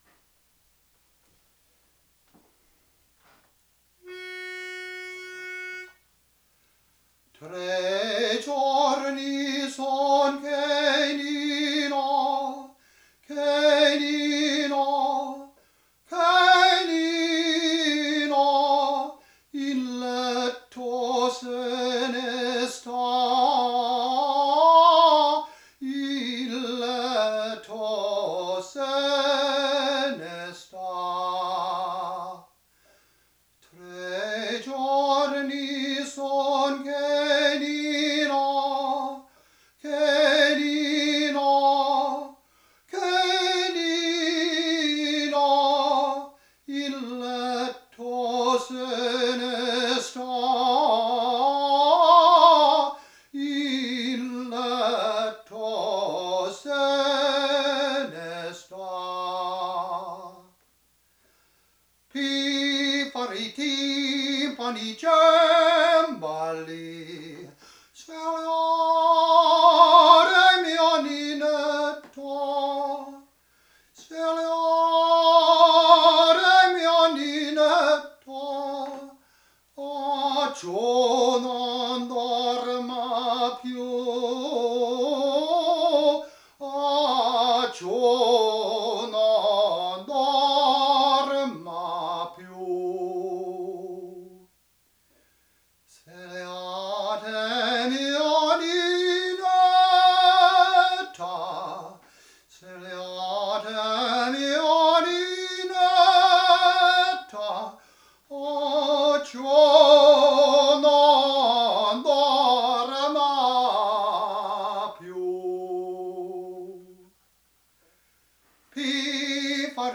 Two ancient (c. 2004) audition recordings:
"Nina" by Legrenzio Vincenzo Ciampi (but often attributed to Pergolesi) from his comic opera "Gli tre cicisbei ridicoli," which premiered at the King's Theatre, London, 1748 (AIFF format, 16,861,042 bytes).
Home on the tenor.